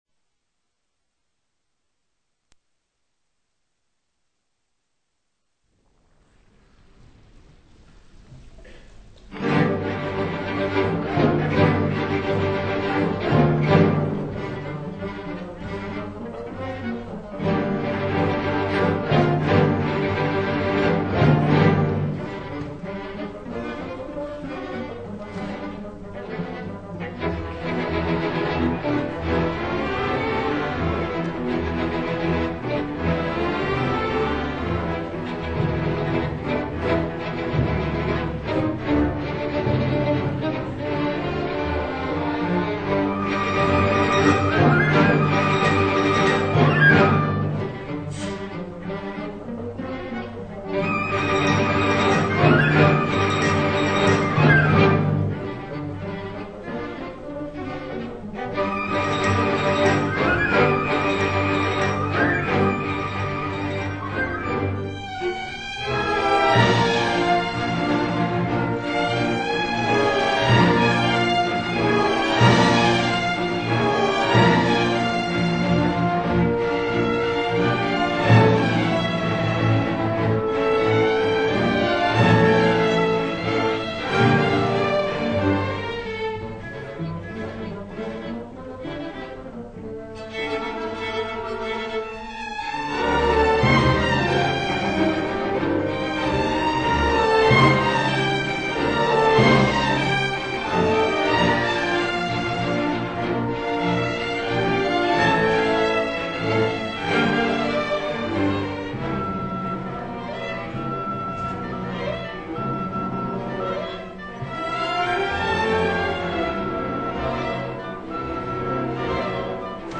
performed by Suburban Symphony Orchestra